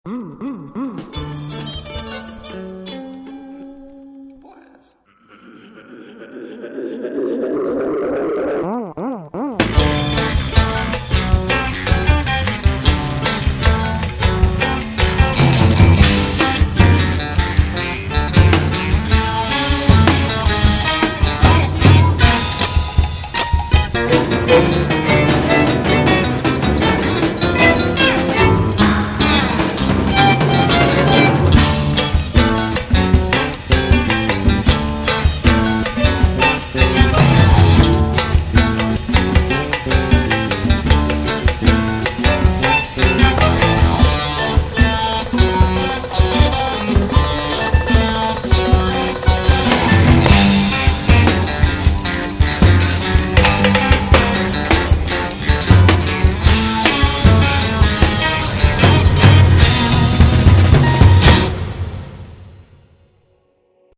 100% post-rock-jazz